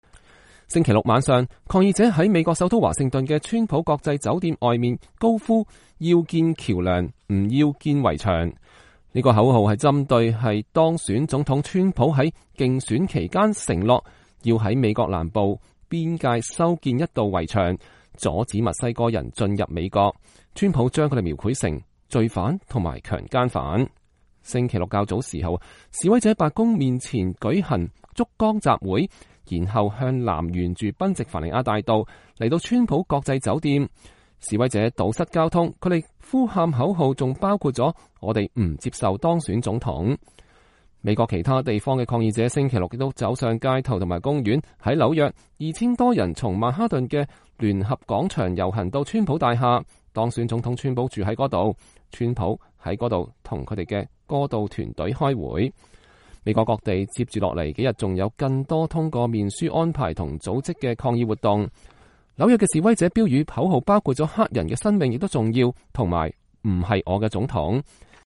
抗議者在美國首都華盛頓的川普國際酒店外面高呼“建橋不建牆”(2016年11月12日)